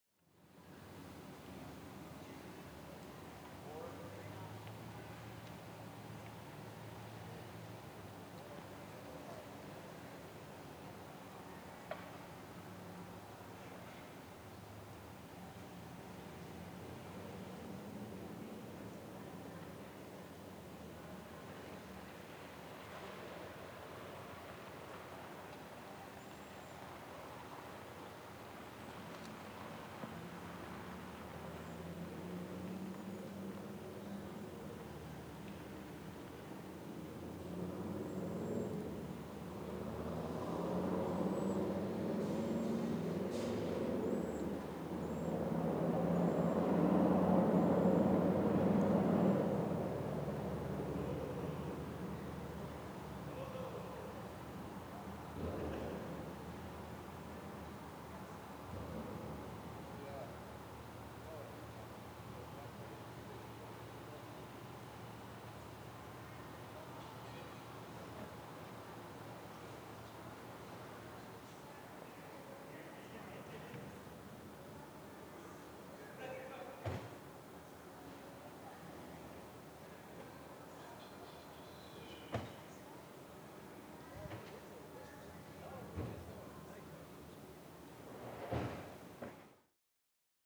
Menominee PowWow 4 Aug 2023 Bowl Distant Set Up.wav